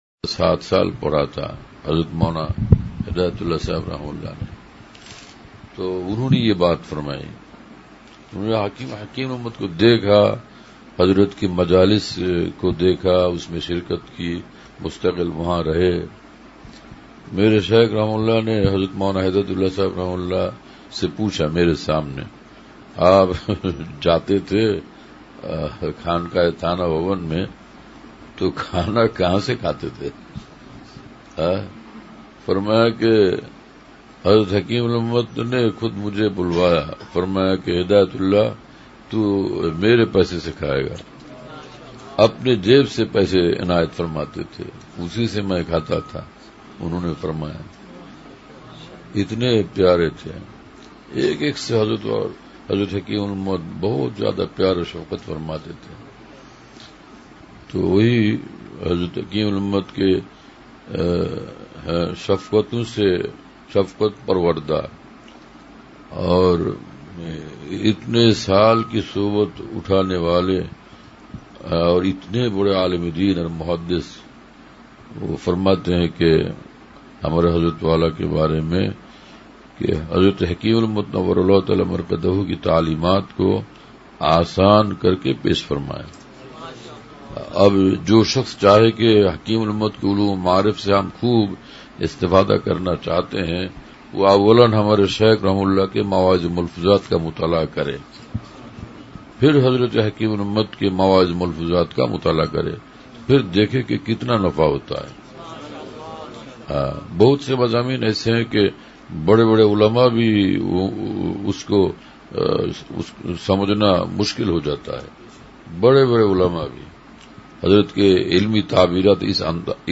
--(غرفہ بعد ظہر و مغرب عظیم الشان علمی و الہامی مجلس،مسجد میں عشاء بیان)--